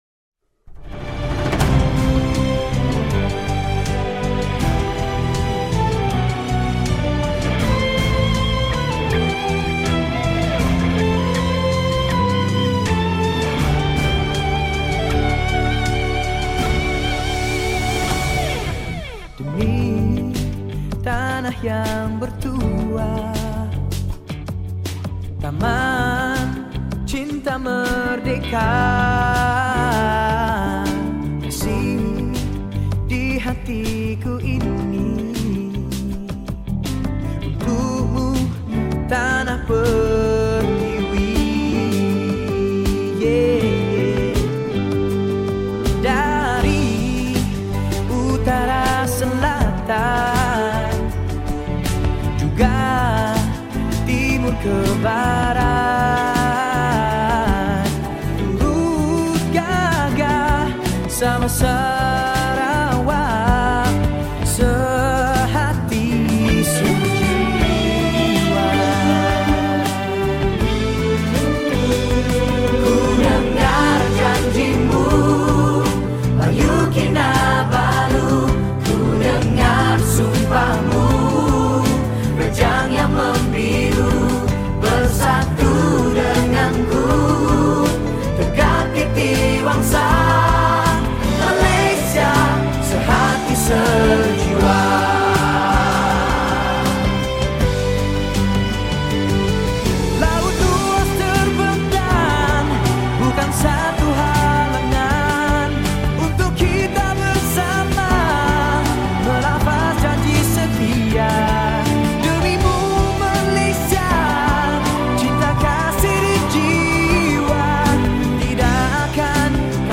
Patriotic Songs